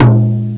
.6TomDrum_.mp3